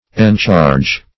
Encharge \En*charge"\, n.